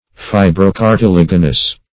Fi`bro*car`ti*lag"i*nous, a.